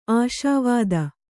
♪ āśāvāda